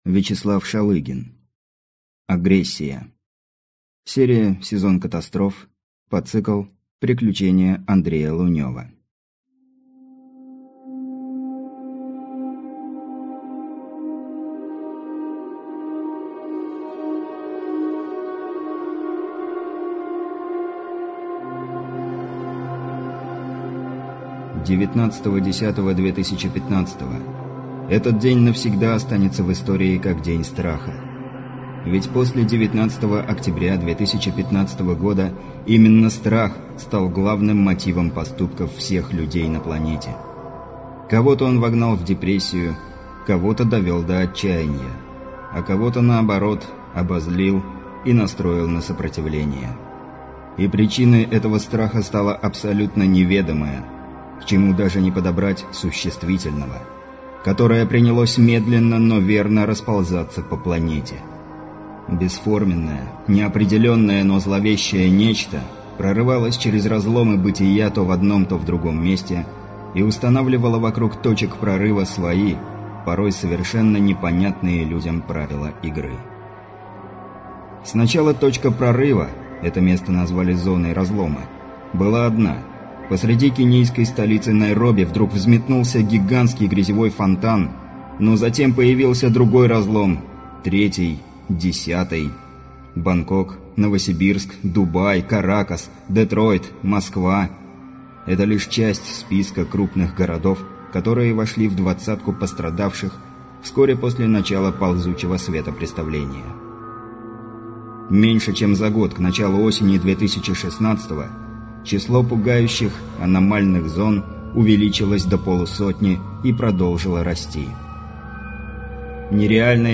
Аудиокнига Агрессия | Библиотека аудиокниг